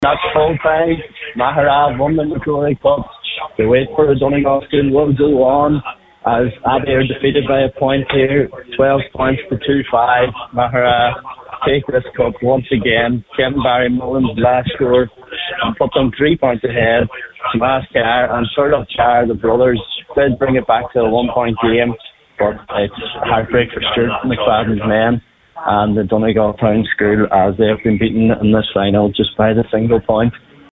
St Pat’s of Maghera have beaten Abbey Vocational School in the MacRory Cup Final at Celtic Park in Derry this afternoon.